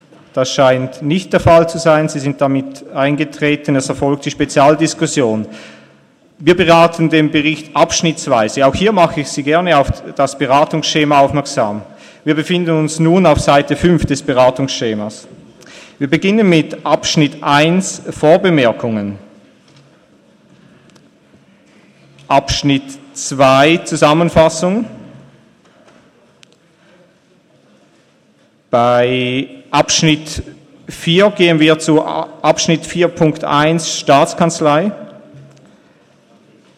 Session des Kantonsrates vom 12. und 13. Juni 2017